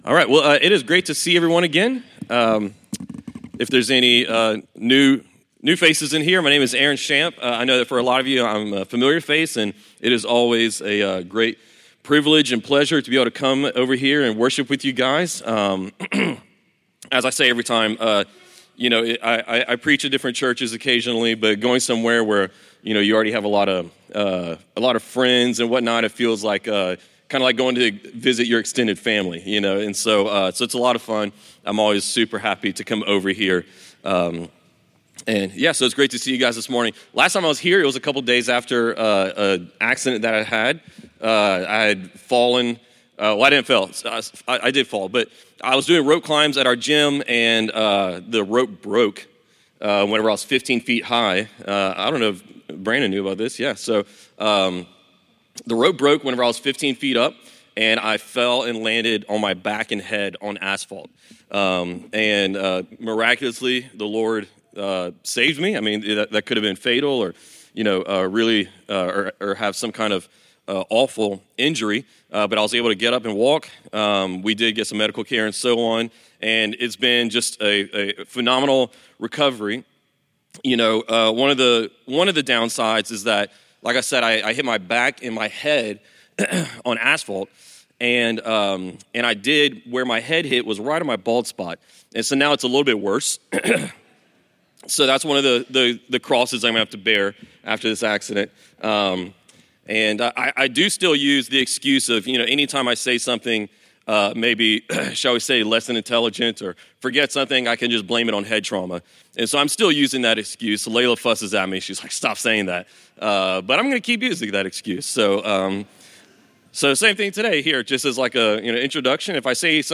This is a part of our sermons.